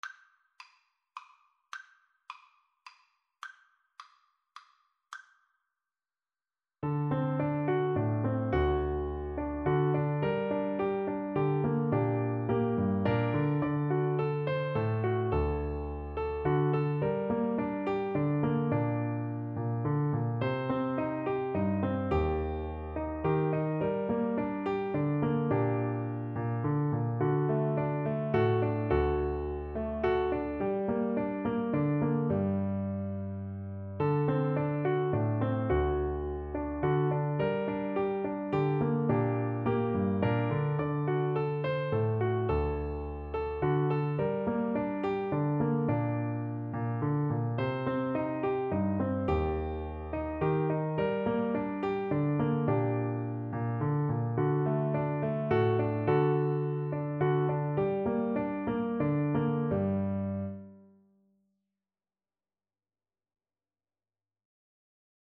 Slow Waltz = c. 106
G minor (Sounding Pitch) (View more G minor Music for Oboe )
3/4 (View more 3/4 Music)